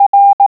Click on a letter, number, or punctuation mark to hear it in Morse code.